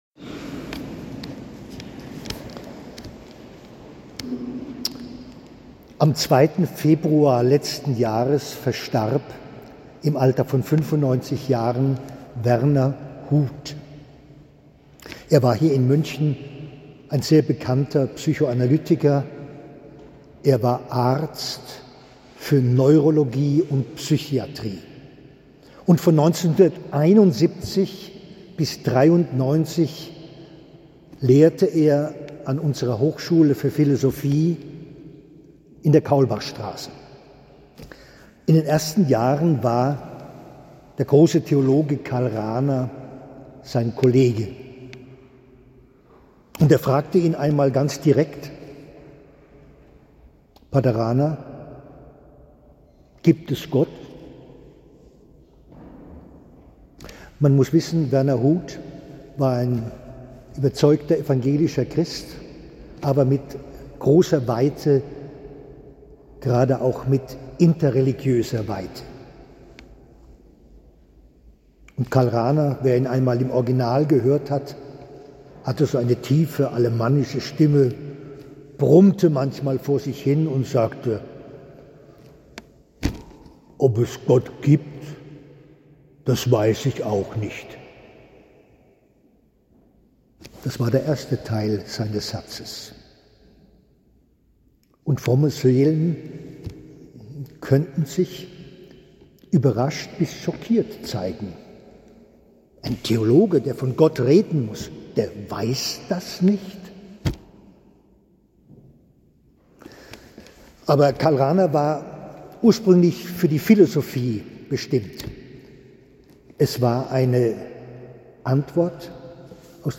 Predigt
Bürgersaalkirche